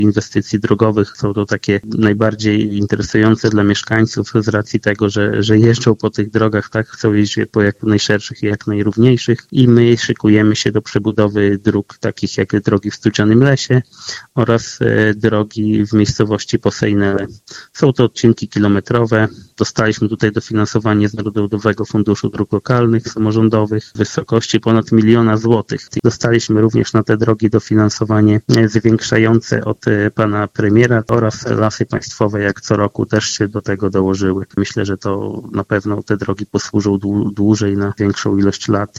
O szczegółach mówił w Radiu 5 Robert Bagiński, wójt gminy Giby.